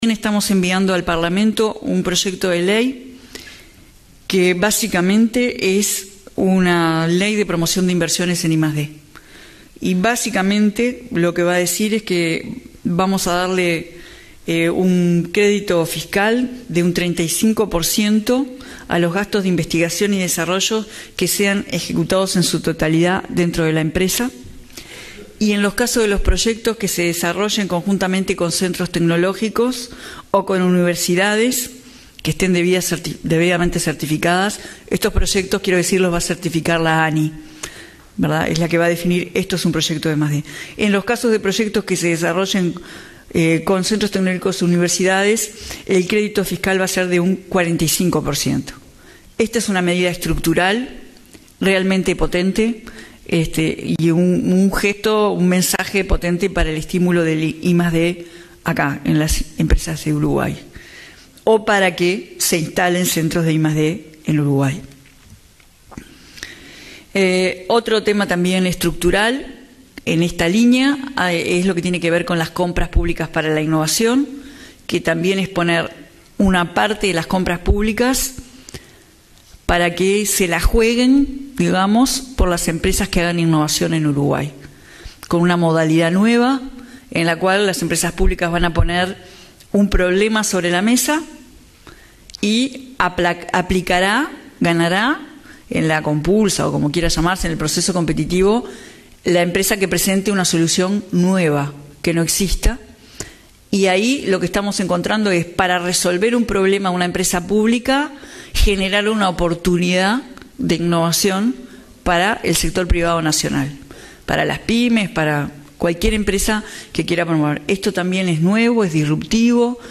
El Gobierno enviará al Parlamento un proyecto de ley de promociones de inversiones en investigación y desarrollo, otorgando crédito fiscal de hasta 35 % para gastos en I+D en las empresas, que aumenta al 45 % si se realiza en conjunto con centros tecnológicos o universidades. Así lo anunció la ministra Cosse este miércoles en un desayuno de trabajo donde también habló de un plan piloto de compras públicas para la innovación.